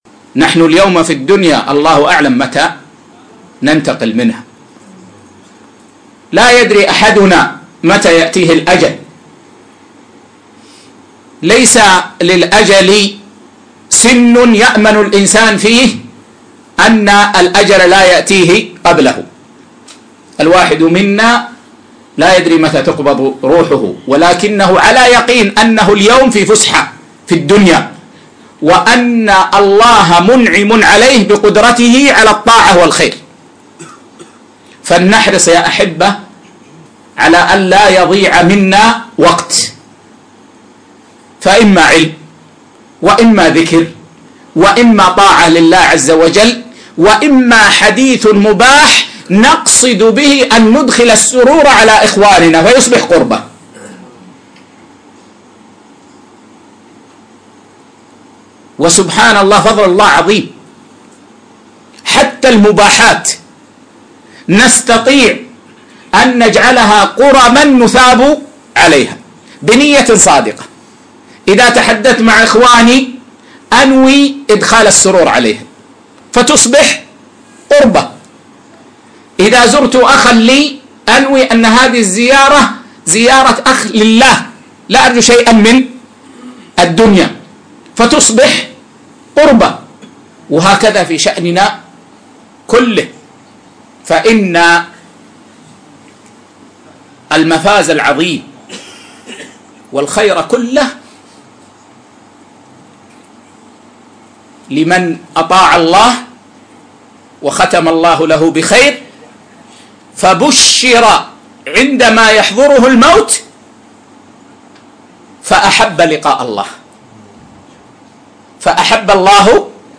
مقتطف من اللقاء المفتوح